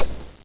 q3rally/baseq3r/sound/weapons/machinegun/buletby1.ogg at 1fc1ccc3c2e8b4bab37a093238c0a3a2a7978ea3